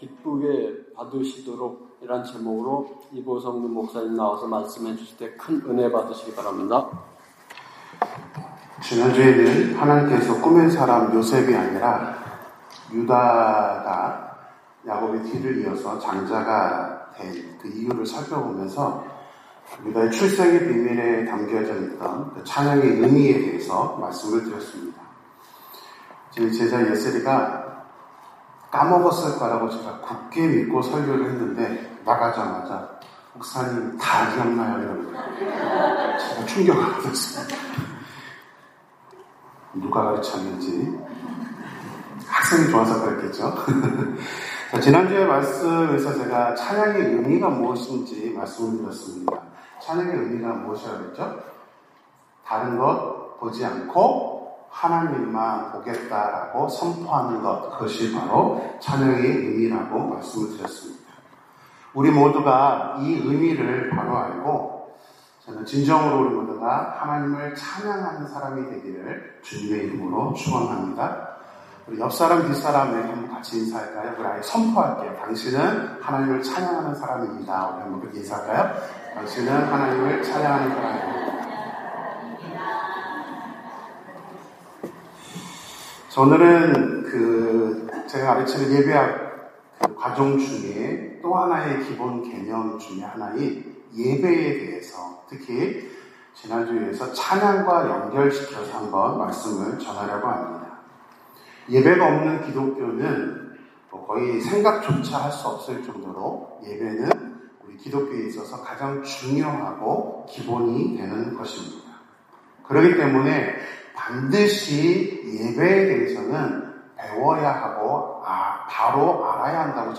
Bible Text: 레 1:3 | 설교자